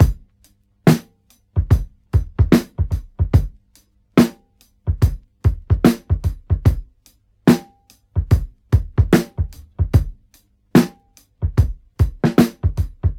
• 73 Bpm Drum Beat G# Key.wav
Free drum loop - kick tuned to the G# note. Loudest frequency: 400Hz
73-bpm-drum-beat-g-sharp-key-COf.wav